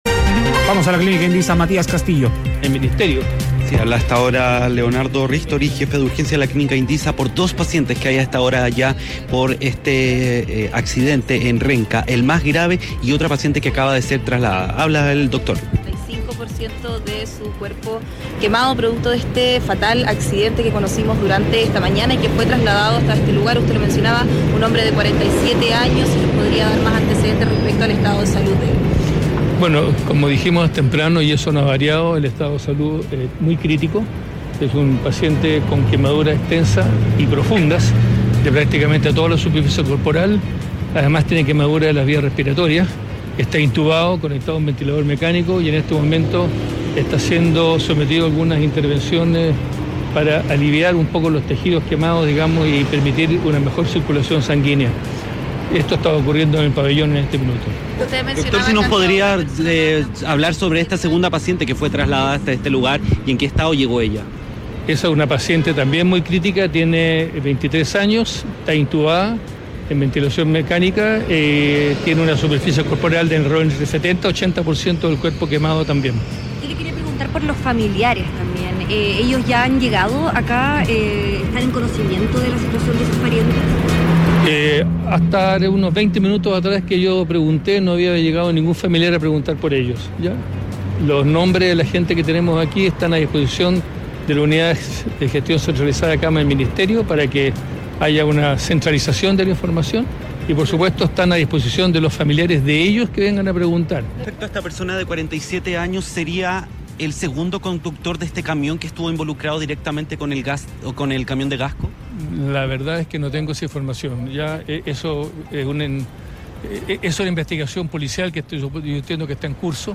Uno con casi todo su cuerpo quemado y otra con el 80% afectado: jefe de urgencia explica crítico estado de pacientes tras explosión en Renca